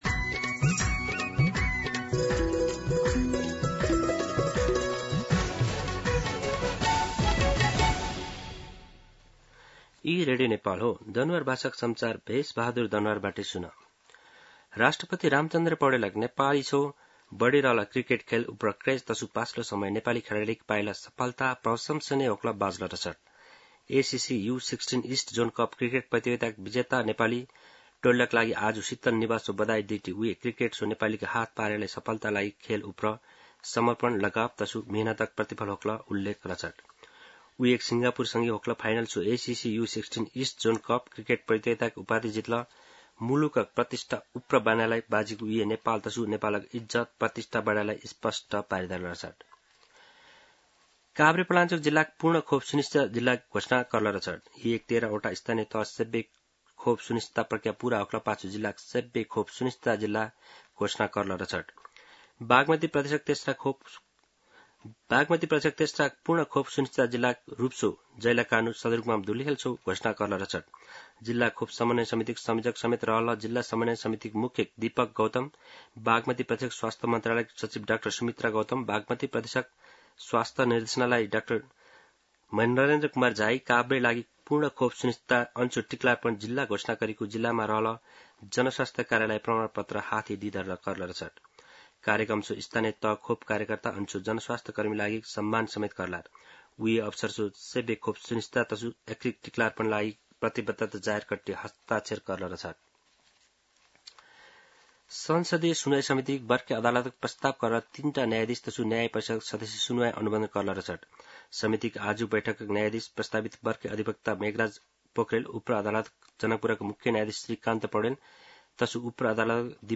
दनुवार भाषामा समाचार : २३ असार , २०८२
Danuwar-News-3-23.mp3